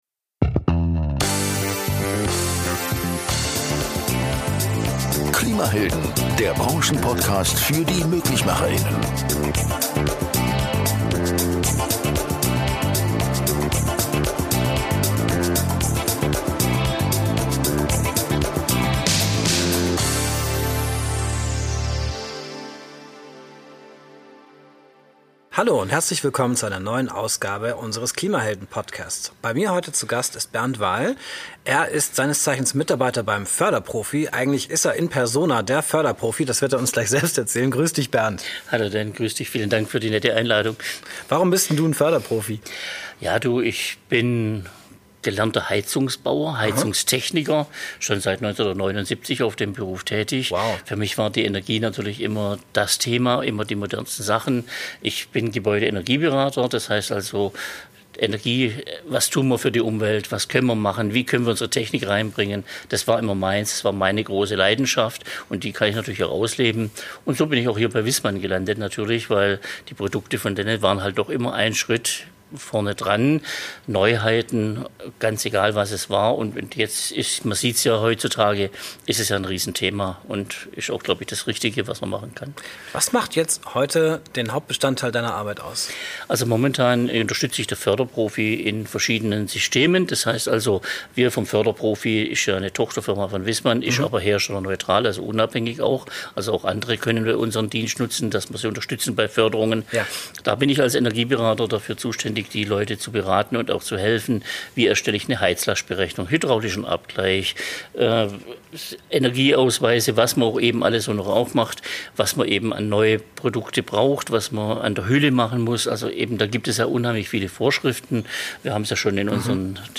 Mit dem Viessmann Partner FörderProfi lässt sich schnell heraus finden, welche Möglichkeiten es gibt – sowohl für Heizungsbesitzer, als auch für Fachhandwerker. Interviewgast